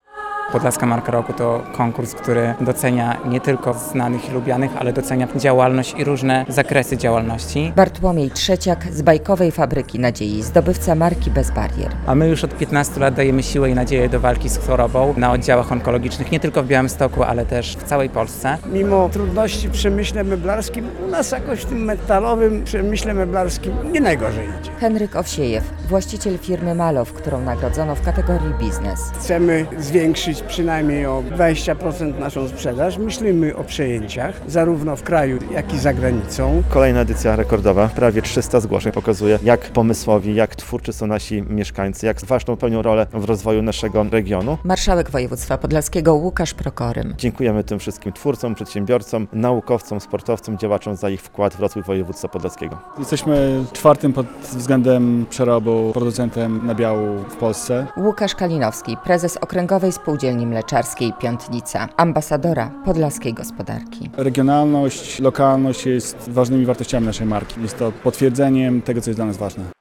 Podlaska Marka - poznaliśmy laureatów prestiżowego konkursu - relacja